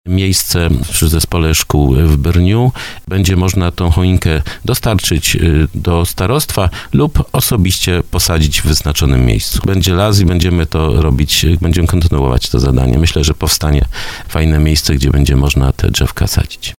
Jak mówił na antenie Radia RDN starosta dąbrowski Lesław Wieczorek, jest już specjalnie wyznaczona przestrzeń, gdzie choinki będą przesadzone.